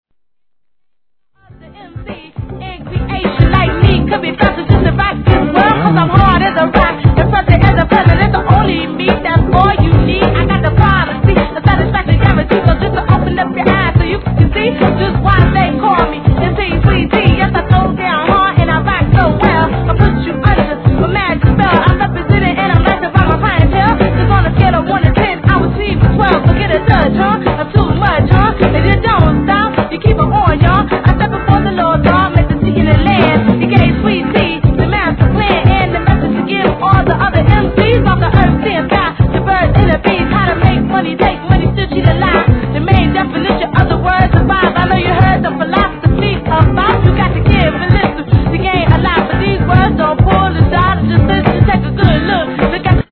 HIP HOP/R&B
定番オールドスクール